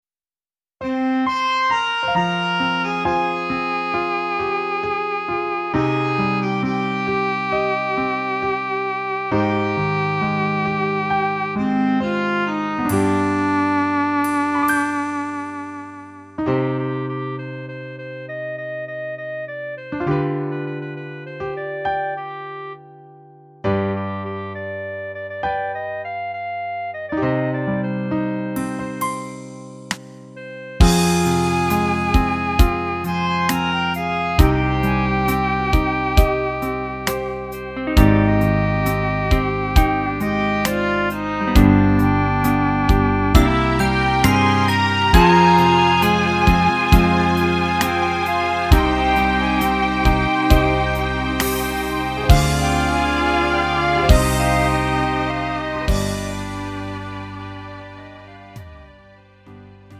음정 원키 3:47
장르 구분 Lite MR